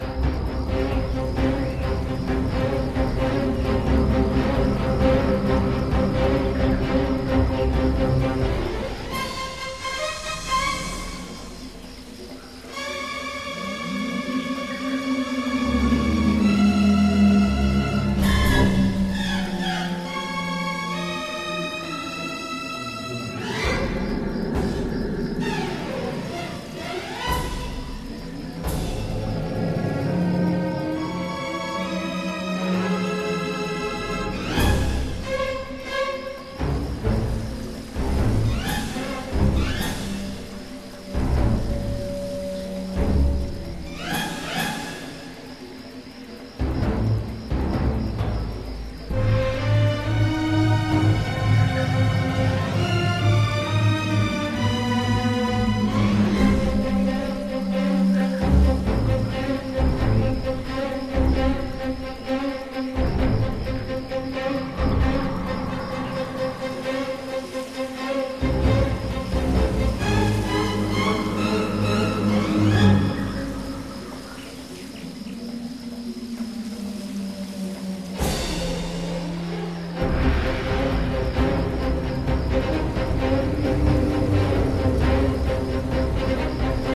Low quality samples from the game XA music files: